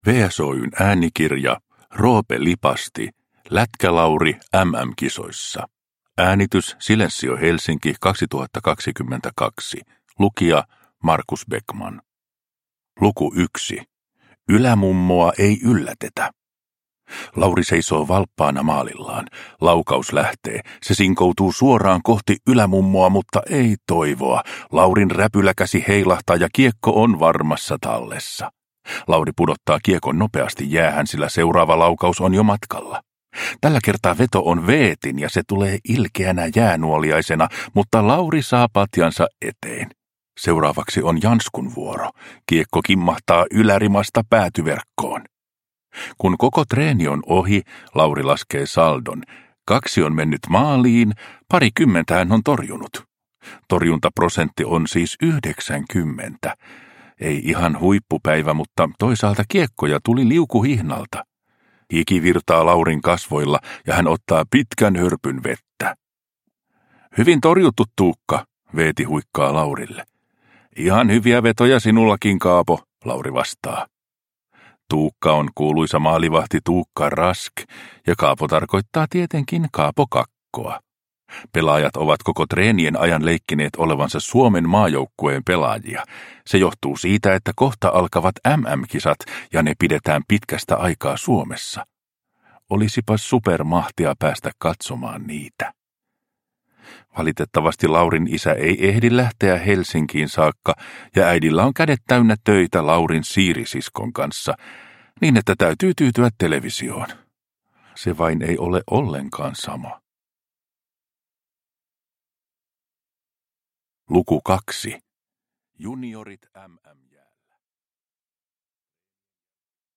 Lätkä-Laurin äänikirja virittää MM-kisojen tunnelmaan.